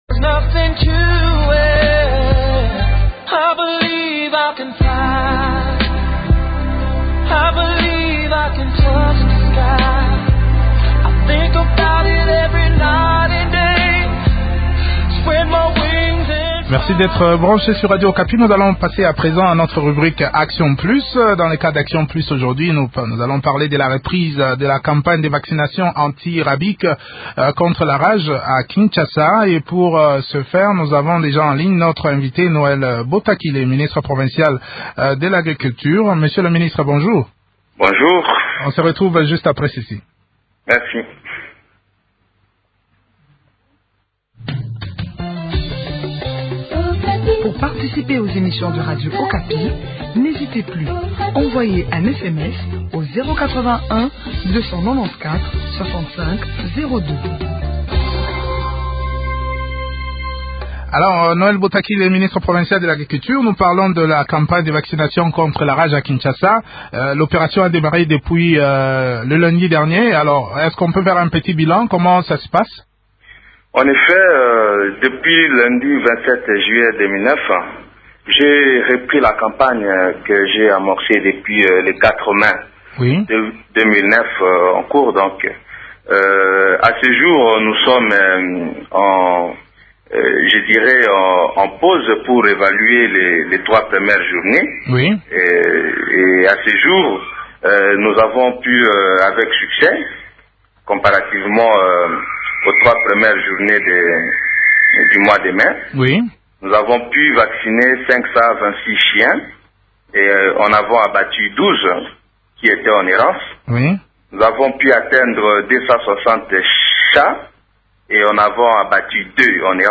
en parle avec Noël Botakile, ministre provincial à l’agriculture, pèche et élevage.